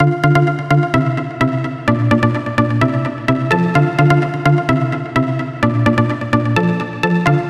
无侧链的静音吉他和弦
描述：声音：静音吉他（nexus2），简单的音符和旋律。
Tag: 128 bpm Dance Loops Synth Loops 1.26 MB wav Key : Unknown